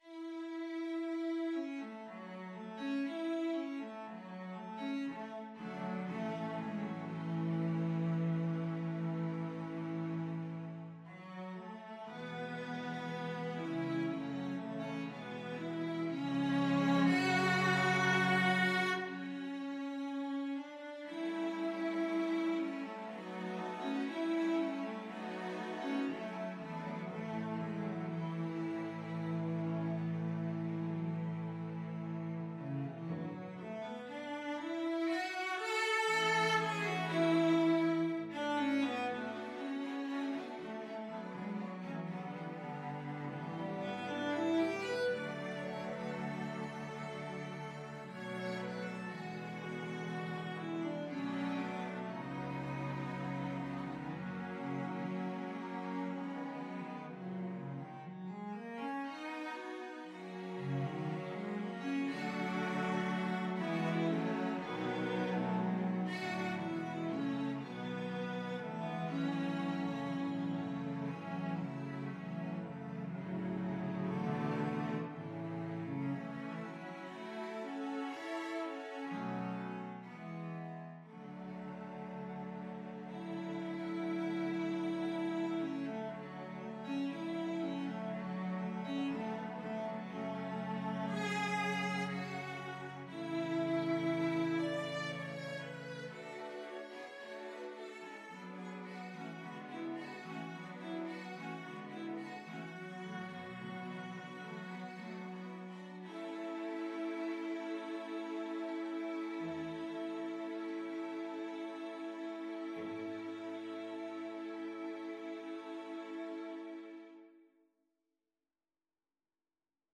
Cello 1Cello 2Cello 3Cello 4Cello 5
Trs calme et doucement expressif =60
3/4 (View more 3/4 Music)
Classical (View more Classical Cello Ensemble Music)